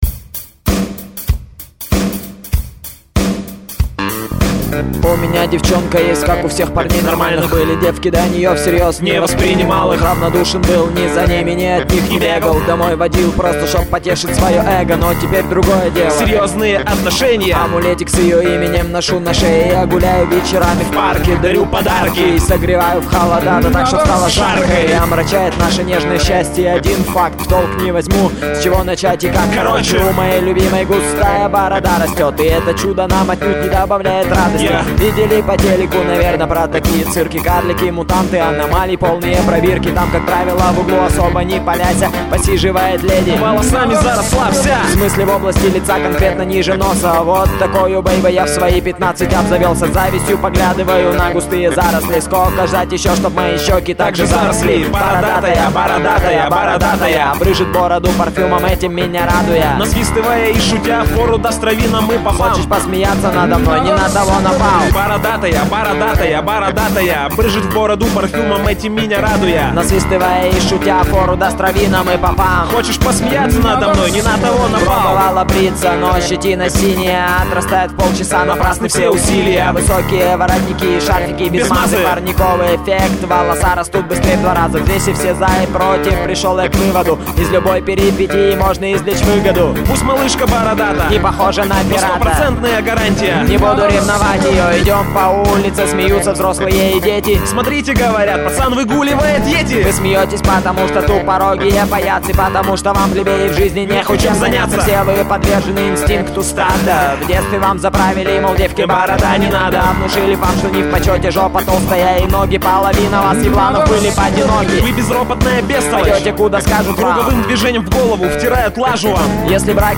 Файл в обменнике2 Myзыкa->Рэп и RnВ
Смешно,немного напоминает "мальчишник"